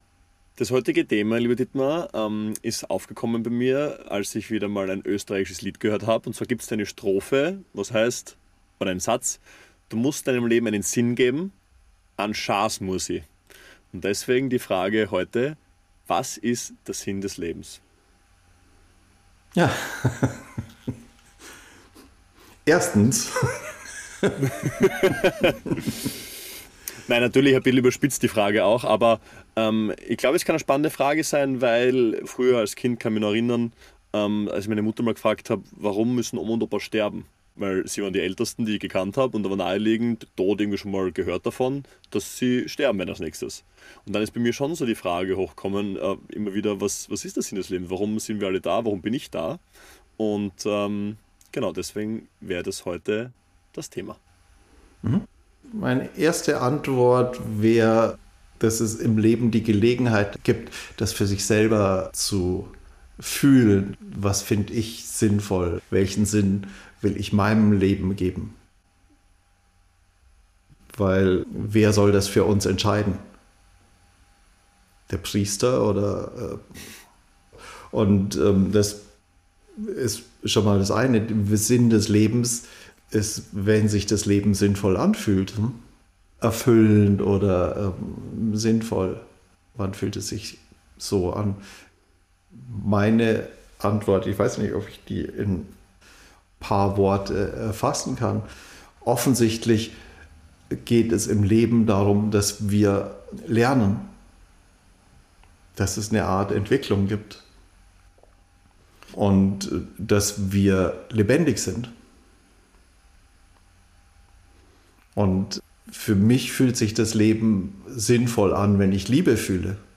Der Sinn des Lebens ist eine der größten Fragen. Eine Antwort in diesem Gespräch: Lebendigkeit entfaltet sich, erweitert seinen Spielraum und erlebt sich selbst – durch uns.